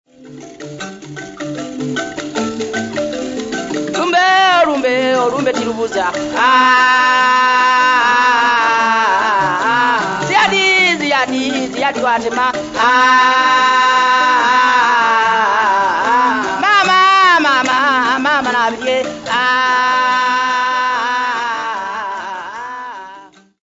Folk music
Field recordings
Africa Uganda Jinja f-ug
sound recording-musical
The singer recalls the deaths of well known people in the district and remarks that death comes to everyone. Lament with 3 Budongo Likembe.